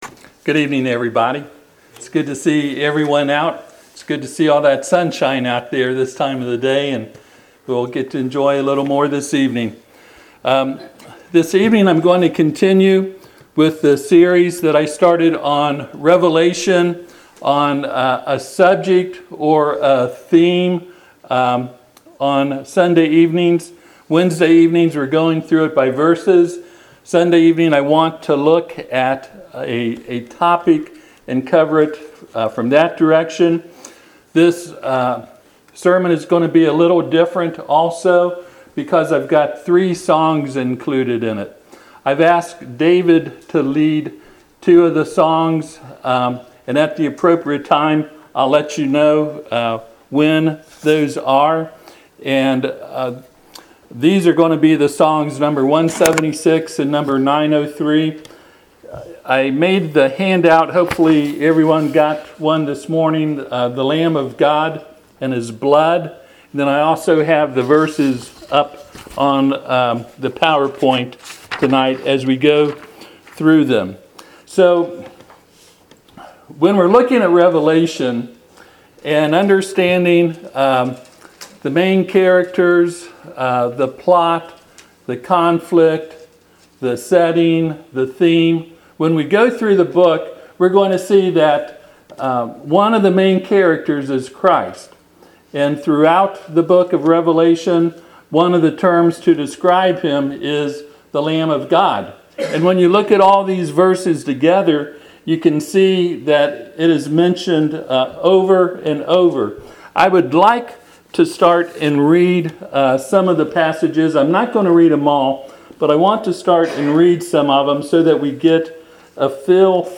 Passage: Revelation 12:11 Service Type: Sunday PM